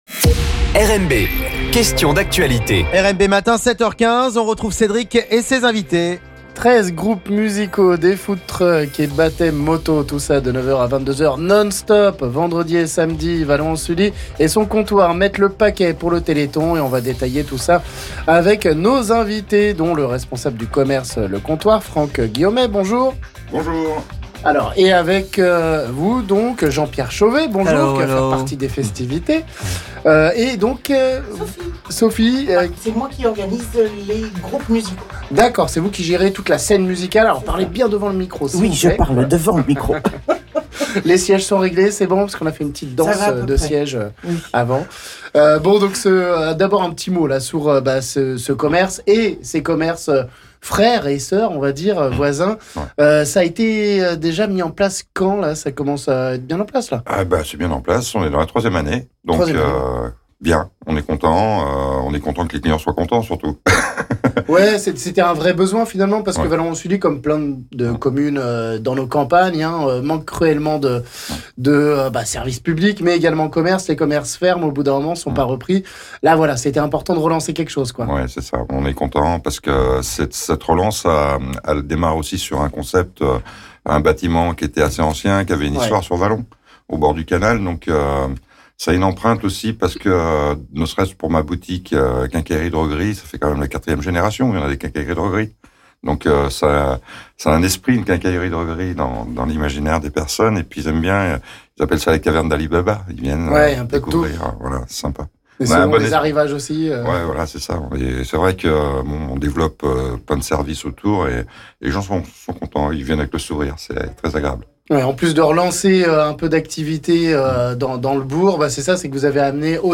On en parle ici avec nos invités...